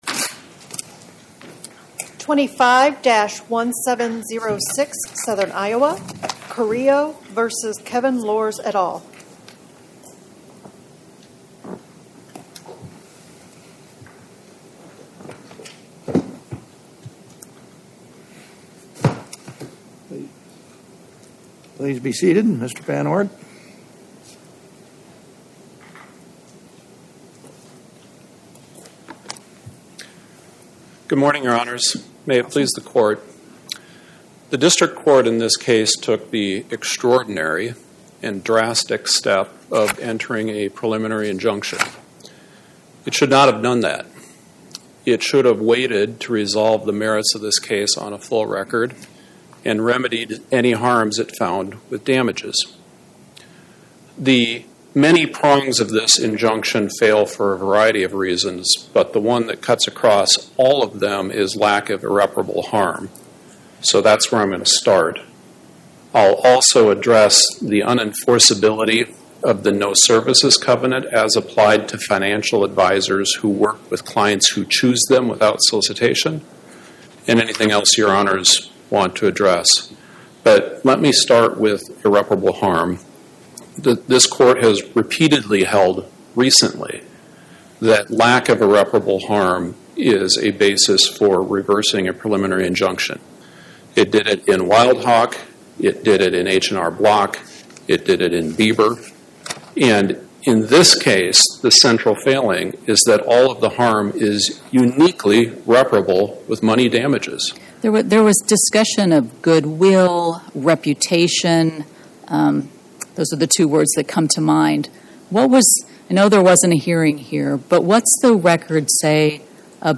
Oral argument argued before the Eighth Circuit U.S. Court of Appeals on or about 09/17/2025